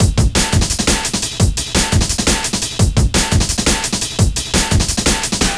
cw_amen19_172.wav